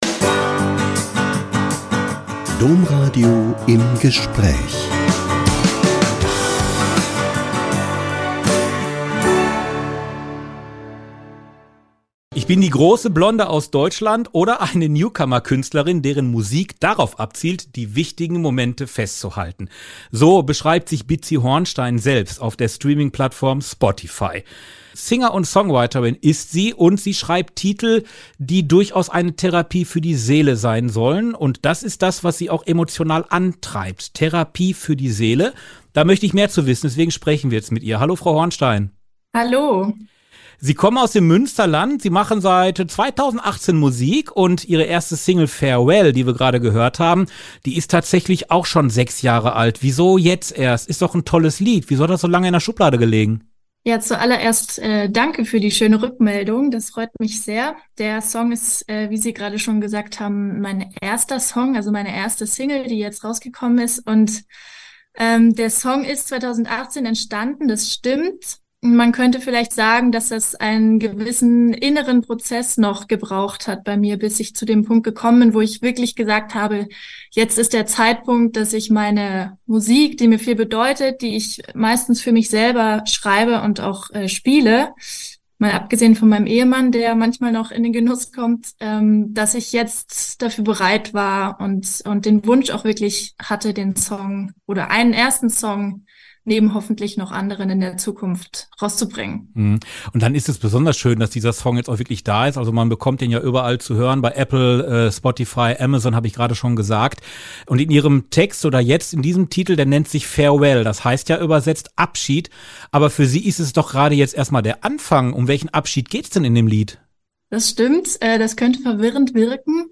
Musikerin spricht über ihr christliches Weltbild und ihr Debut
Podcaster